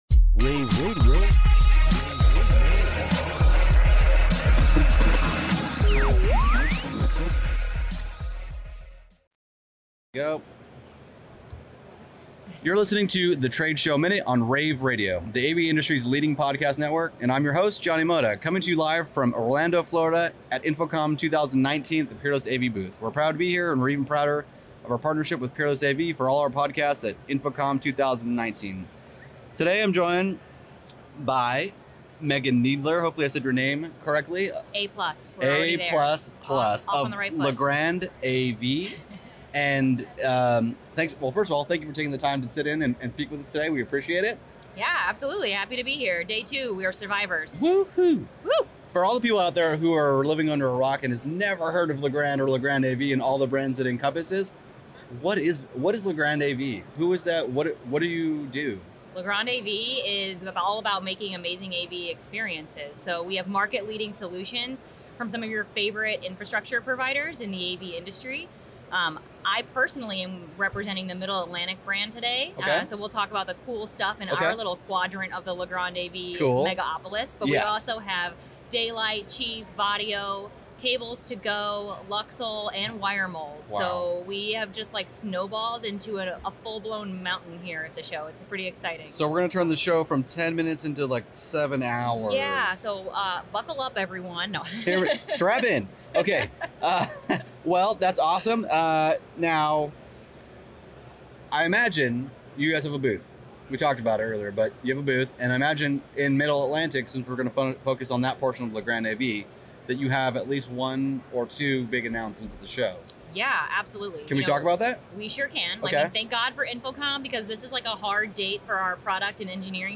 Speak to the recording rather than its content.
June 13, 2019 - InfoComm, InfoComm Radio, Radio, rAVe [PUBS], The Trade Show Minute,